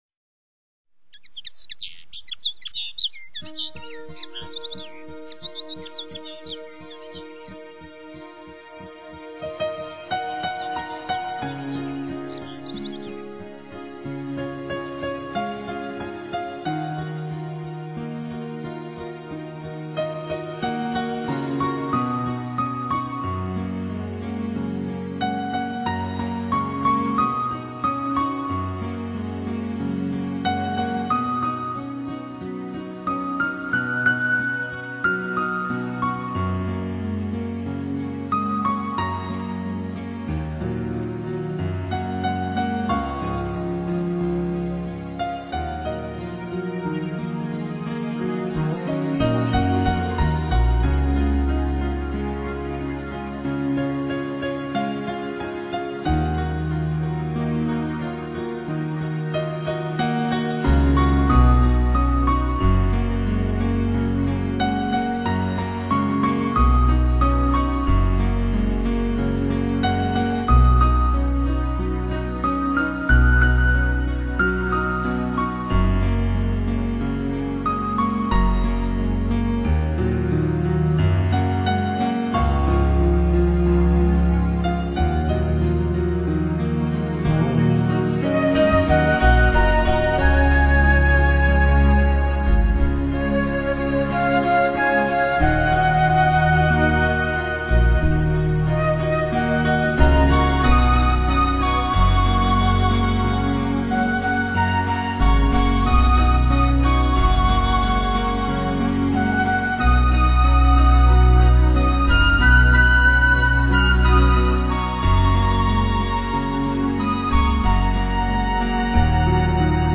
专辑语言：纯音乐
淡淡的音乐，自然的氛围